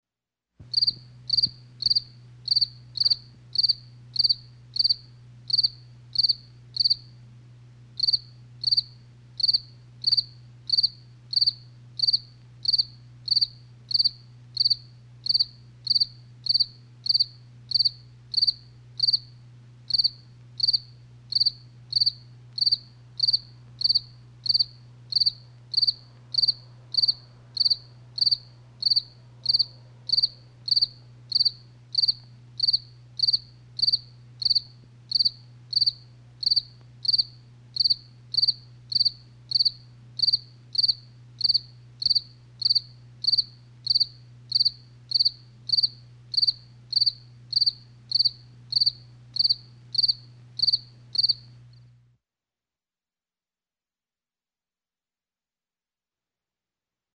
Grillo
Imagínate en esas noches de verano… la ventana abierta… y esa dulce melodía interpretada por…. grillos !!!
tonomovil.net_grillo.mp3